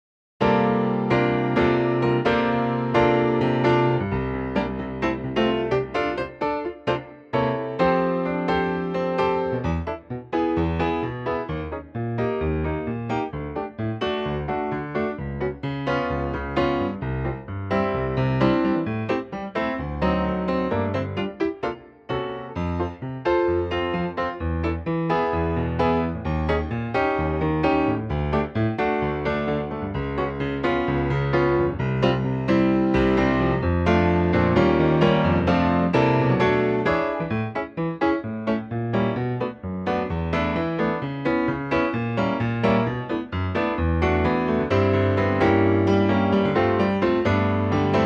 key - F - vocal range - C to A (optional C top note)